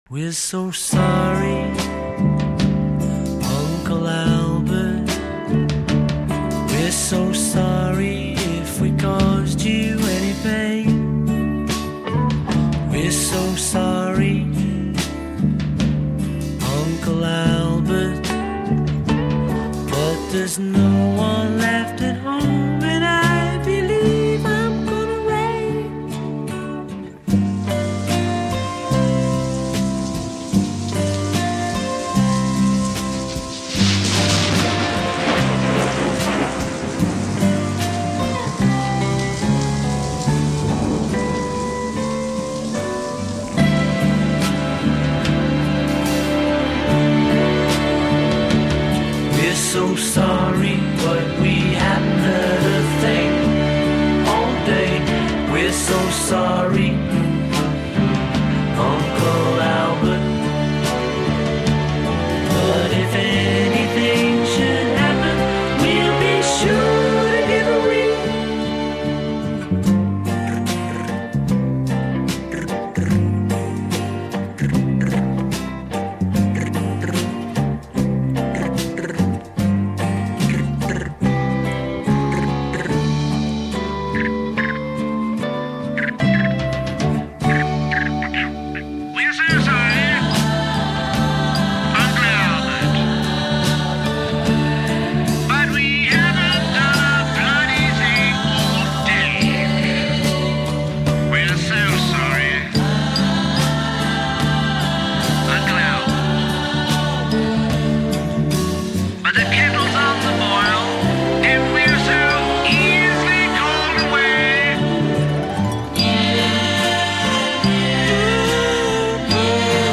записанный в январе-марте 1971 года в RCA Studios, Нью-Йорк